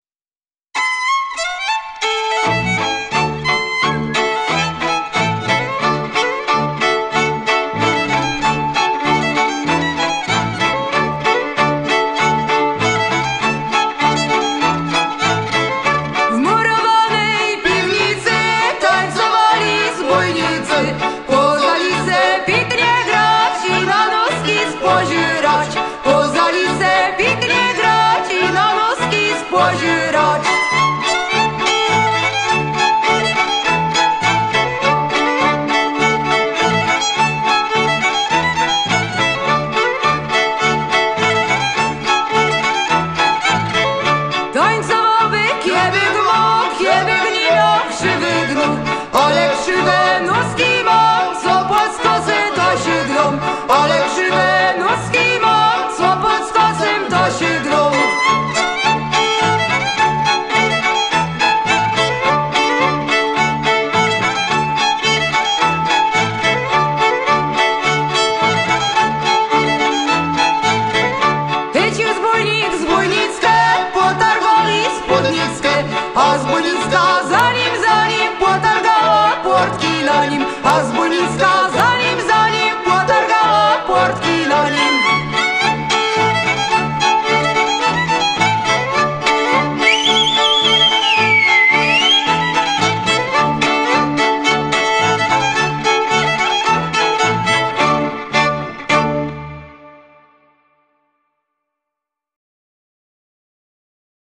Folklor: